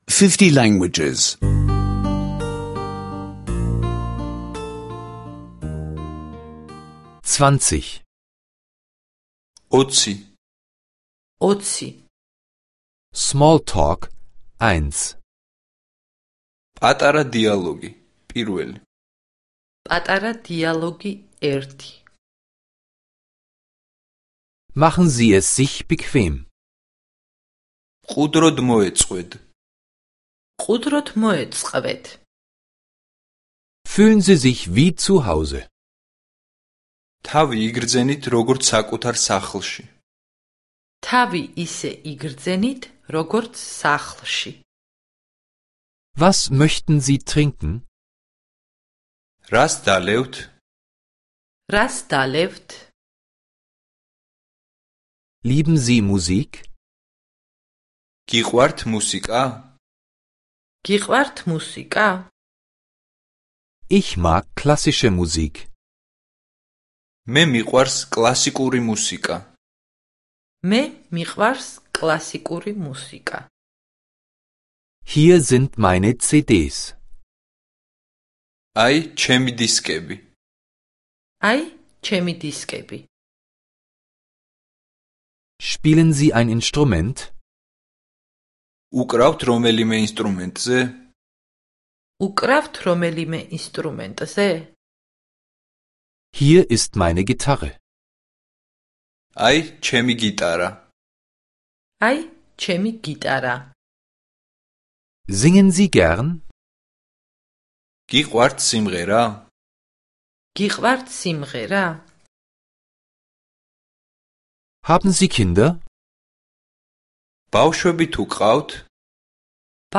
Georgische Audio-Lektionen, die Sie kostenlos online anhören können.